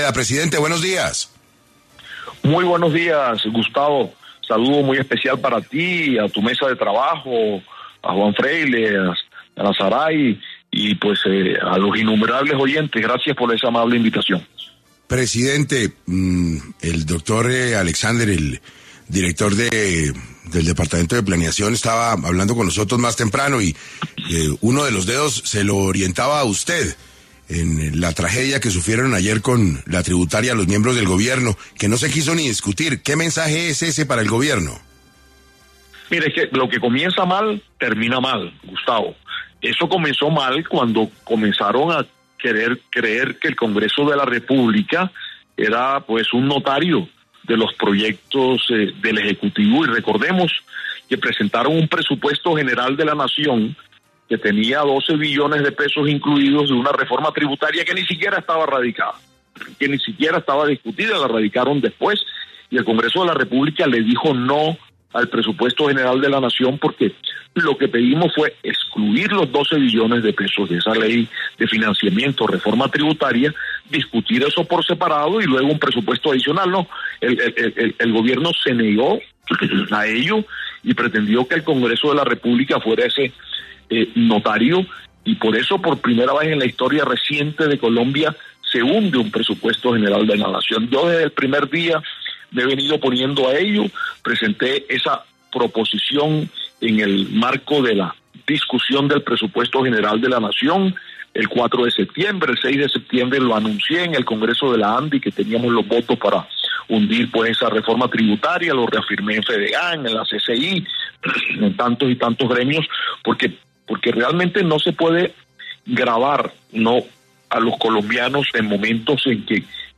En 6AM de Caracol Radio estuvo Efraín Cepeda, presidente del Congreso, quien habló sobre qué representa para el gobierno Petro el hundimiento de la reforma tributaria.